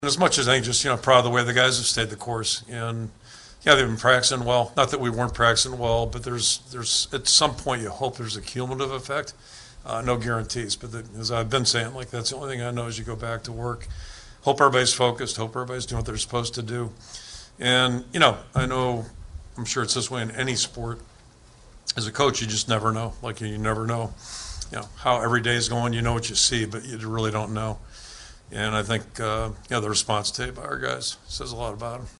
That’s Iowa Coach Kirk Ferentz.